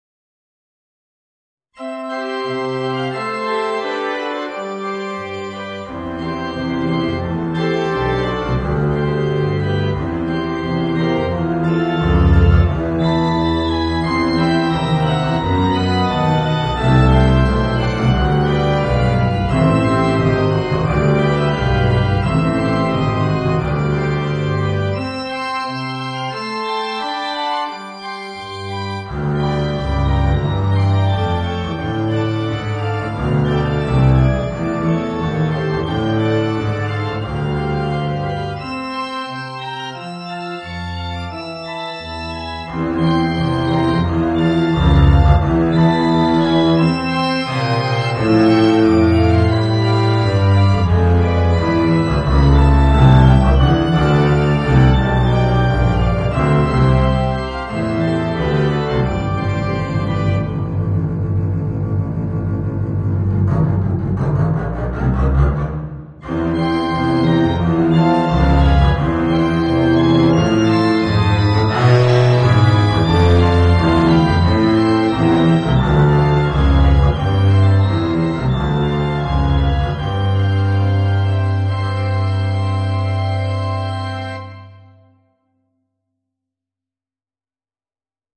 Voicing: Contrabass and Organ